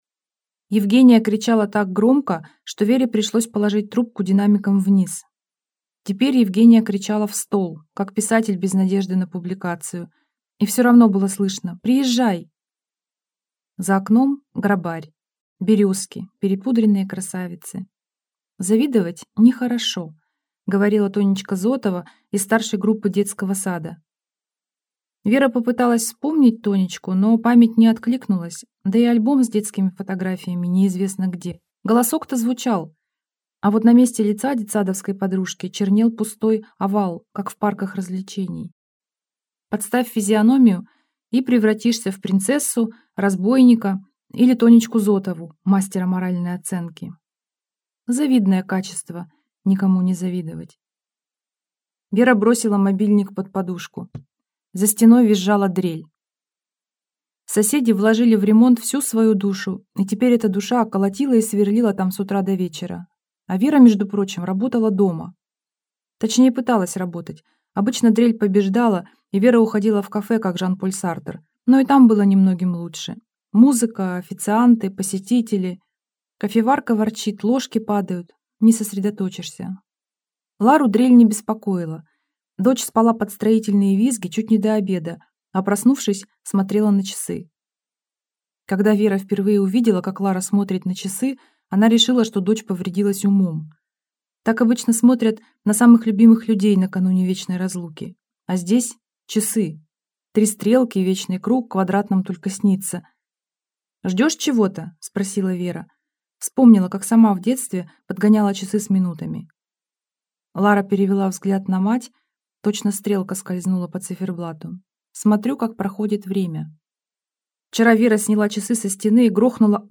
Аудиокнига Завидное чувство Веры Стениной | Библиотека аудиокниг